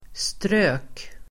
Uttal: [strö:k]